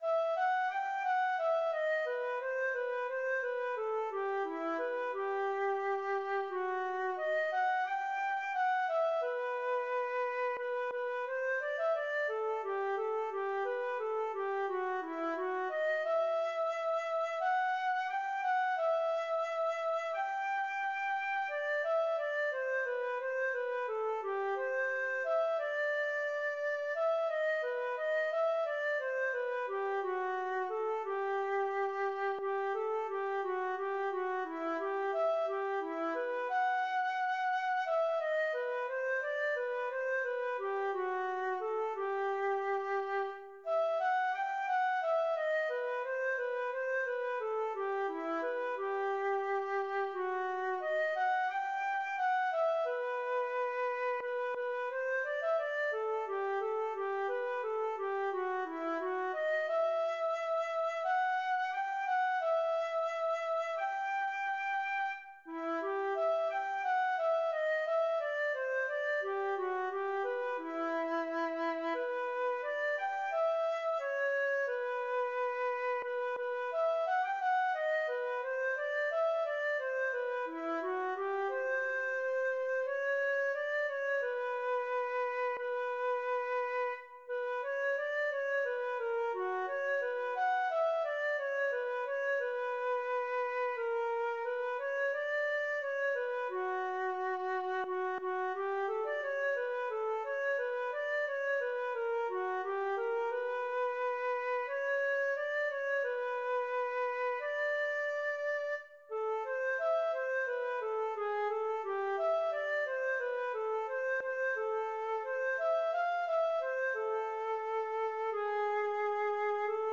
歌詞なし